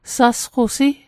Pronunciation Guide: hgas·ku·si